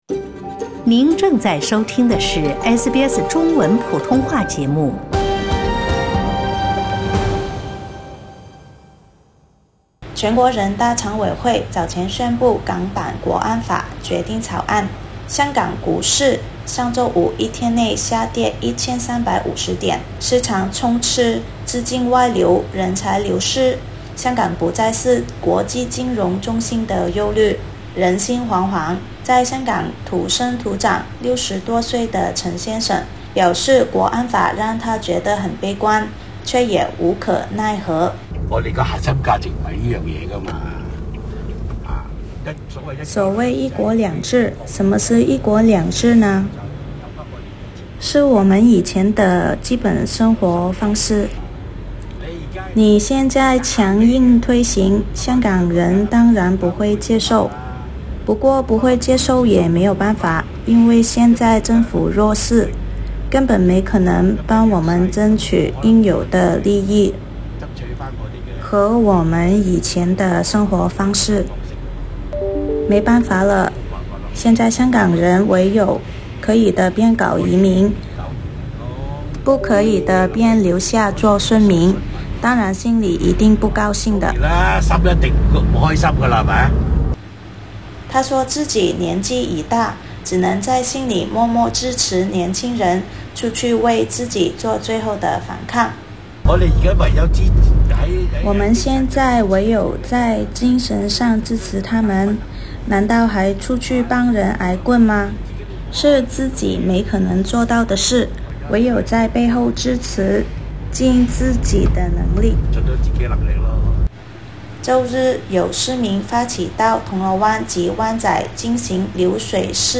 點擊上方圖片收聽錄音報道。